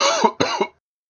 mixkit-sick-male-cough-2225.ogg